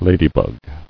[la·dy·bug]